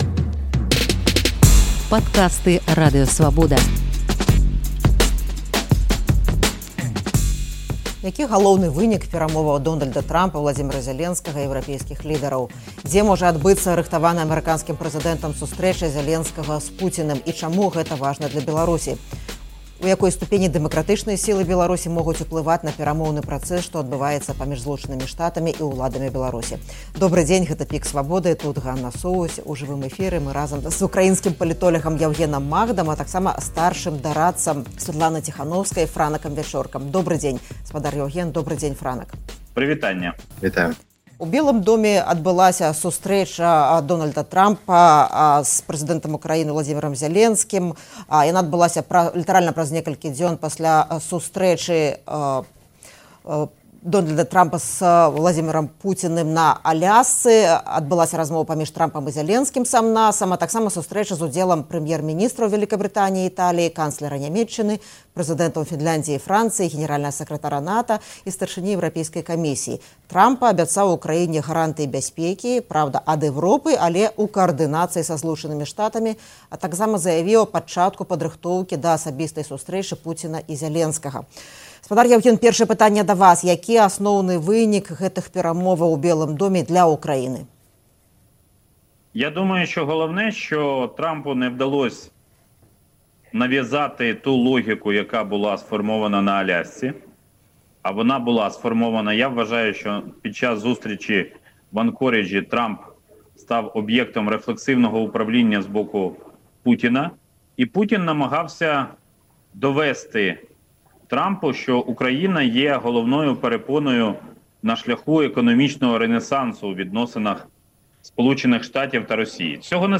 У жывым эфіры «ПіКа Свабоды»